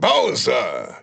The announcer saying Bowser's name in German releases of Super Smash Bros. Brawl.
Bowser_German_Announcer_SSBB.wav.mp3